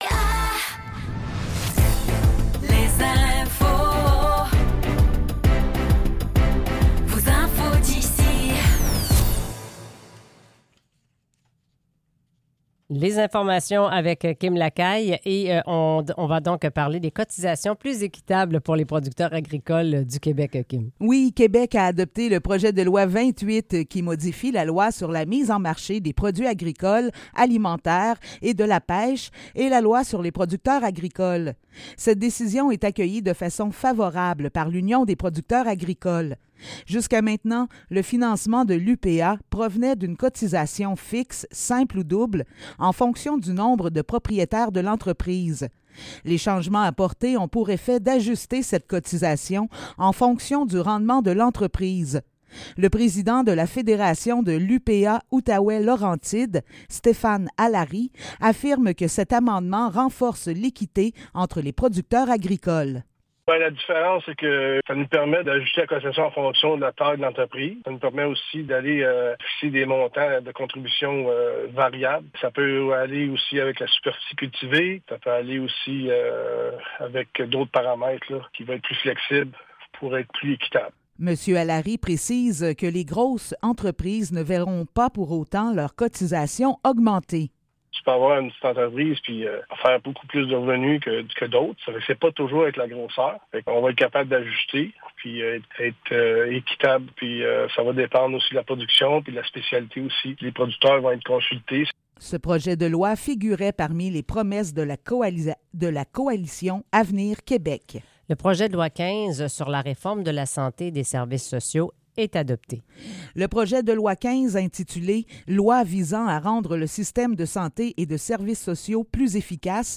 Nouvelles locales - 12 décembre 2023 - 7 h